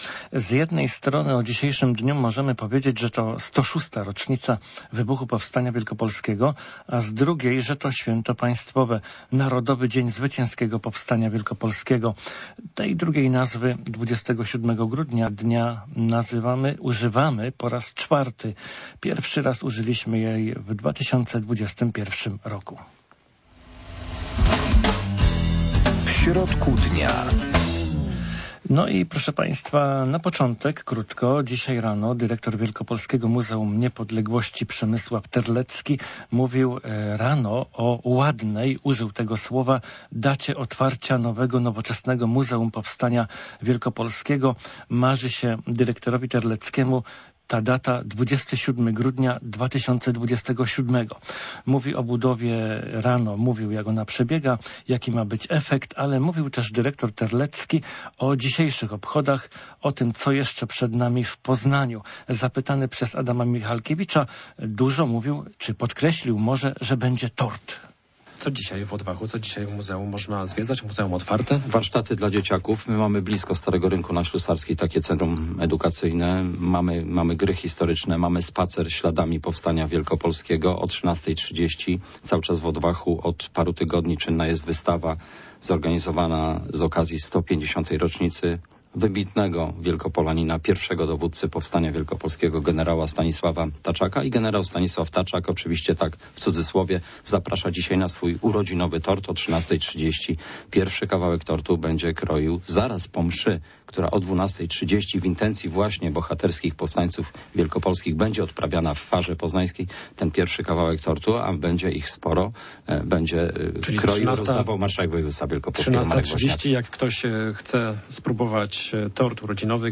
Łączyć się będziemy z naszymi reporterami, ale i Państwo będą mogli zadzwonić, aby opowiedzieć, czy i jak świętują ten dzień.